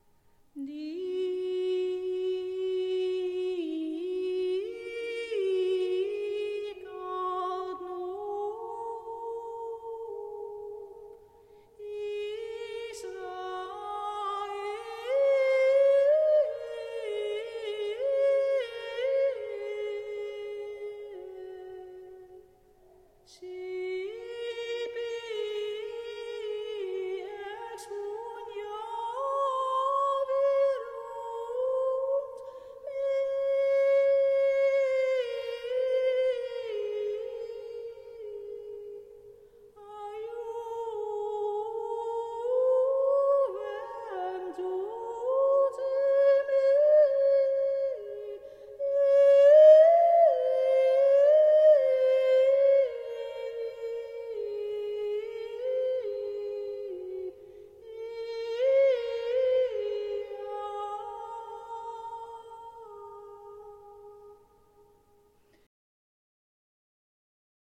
Dicat (Ps. 128, 2), tractus  WMP   RealPlayer